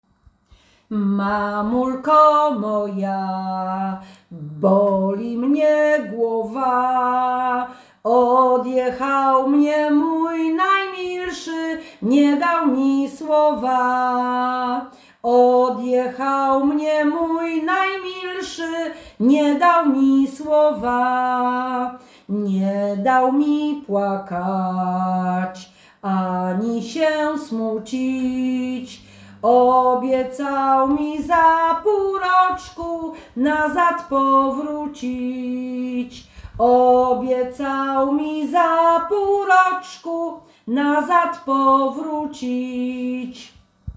Nagranie współczesne